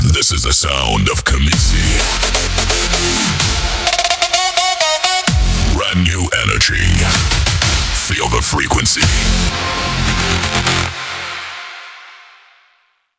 Global Pop Dance Producer | Catchy Radio-Ready Hits